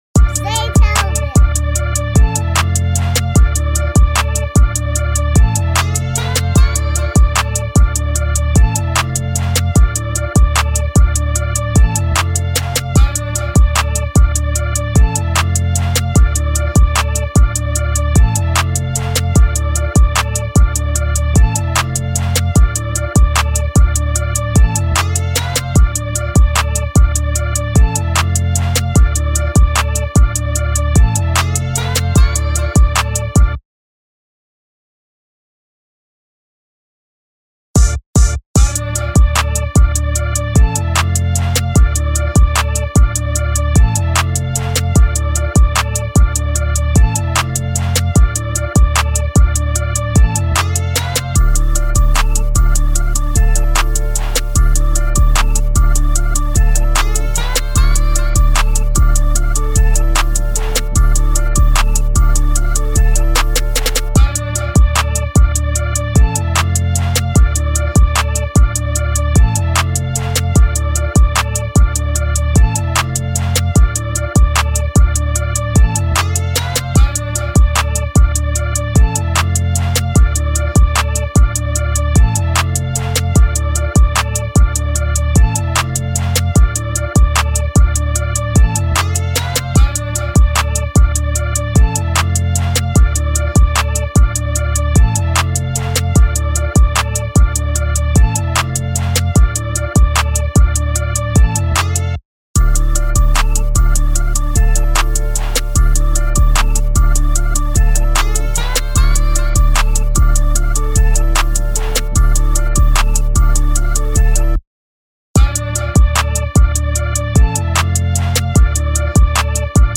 2025 in Hip-Hop Instrumentals